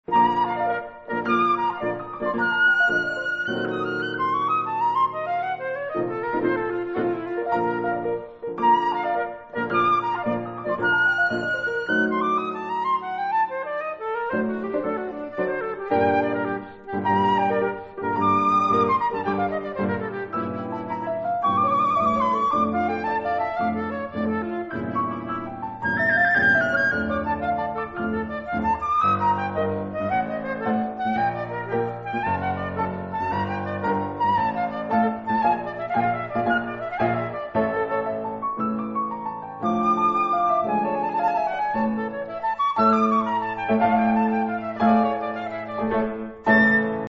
flute
3 romantic sonatas
piano